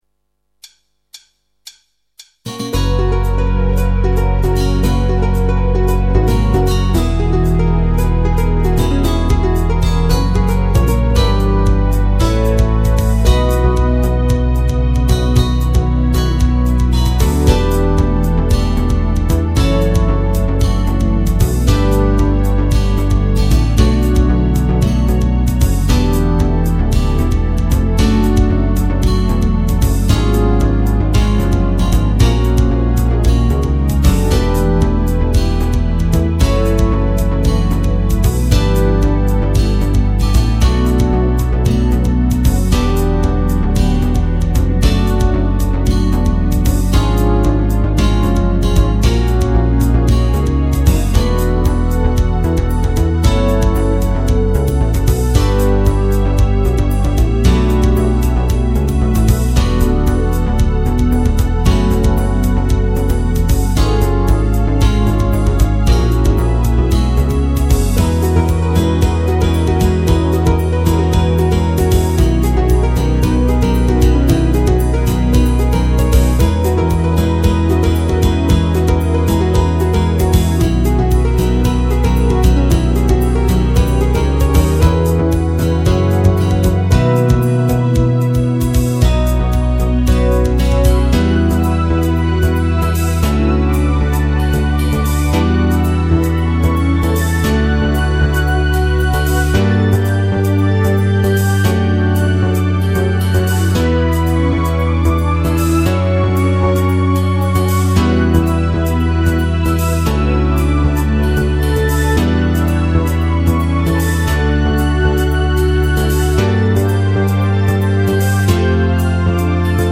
（5MB）※メロディ無しバージョン